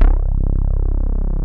THINBASSC2-R.wav